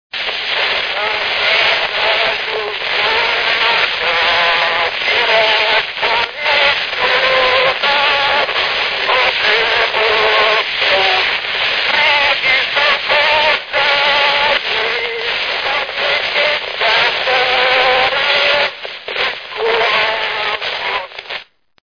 Dunántúl - Zala vm. - Csonkahegyhát
Műfaj: Gergelyjárás
Stílus: 7. Régies kisambitusú dallamok